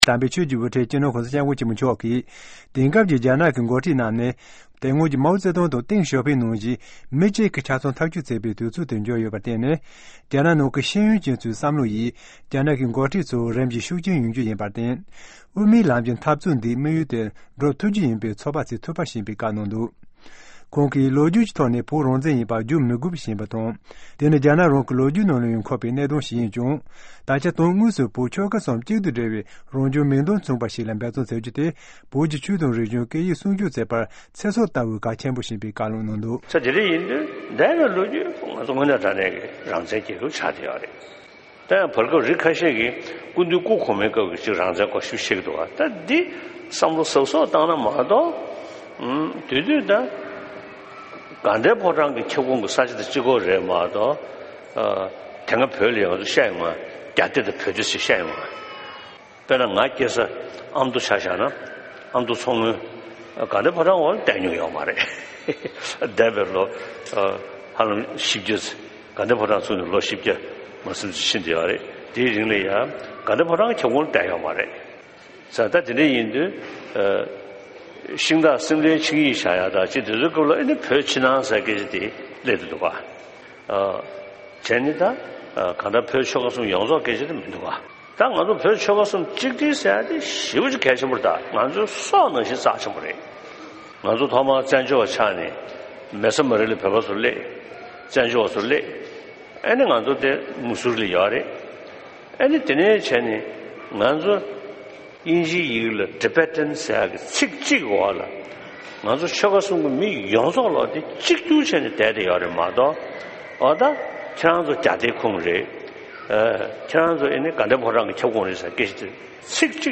༧གོང་ས་སྐྱབས་མགོན་ཆེན་པོ་མཆོག་གིས་ཕྱི་ཟླ་༥་པའི་ཚེས་༡༥་ཉིན་ཨ་མི་རི་ཁའི་ཝིན་སི་ཁོན་སིན་མངའ་སྡེའི་མེ་ཌེ་སན་གྲོང་ཁྱེར་གྱི་བོད་པ་དང་ས་གནས་གཞན་ནས་ཡོང་བའི་བོད་པ་ཚོར་དམིགས་བསལ་དུས་ཆུ་ཚོད་གཅིག་ལྷག་ཙམ་རིང་ལ་བཀའ་སློབ་གནང་སྟེ།